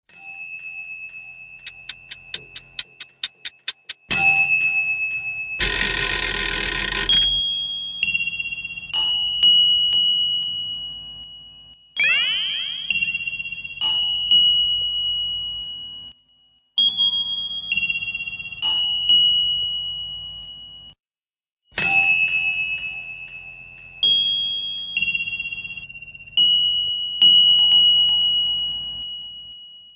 Disturbed Ticking Bell Ringtone
This is a 30 second MP3 Ringtone titled: Disturbed Ticking Bell Ringtone.
DisturbedTickingBellRingtone.mp3